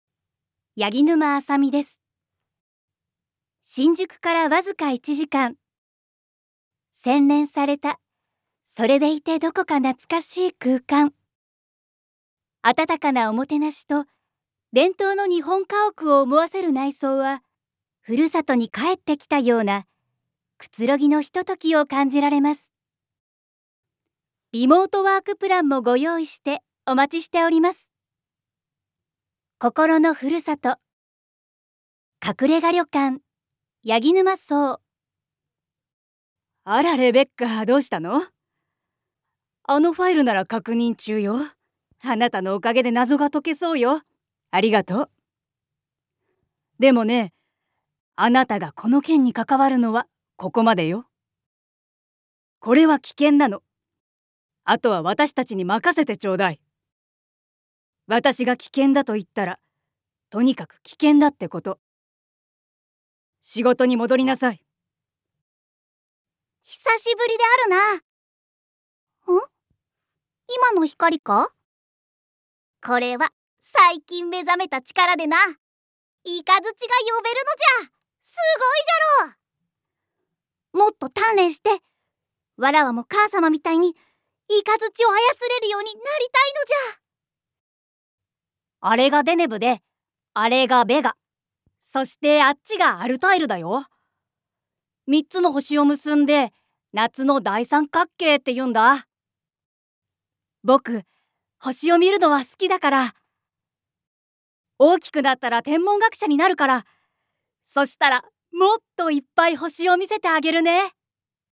落ち着いた感じ One shot Voice（サンプルボイスの視聴）
キャラクター おとなしい少女、少年、大人の女性